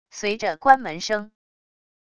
随着关门声wav音频